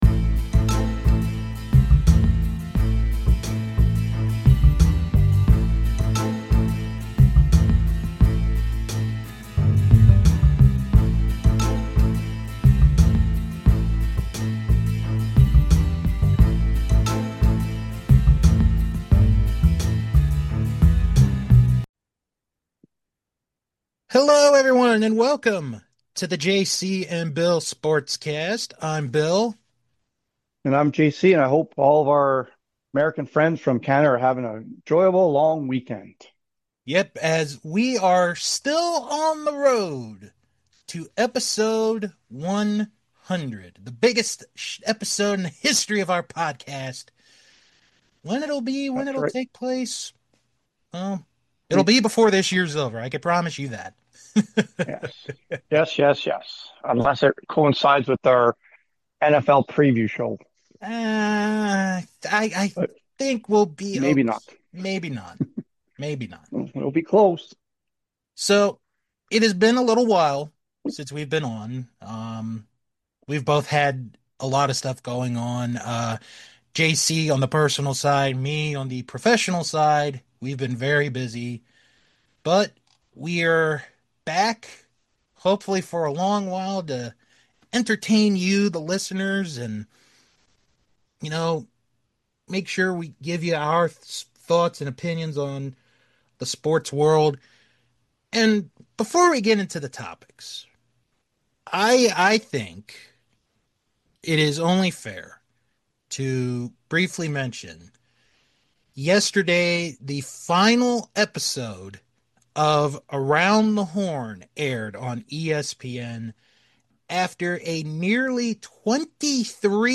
Join the guys as they discuss the Colorado Rockies and how did they get so bad???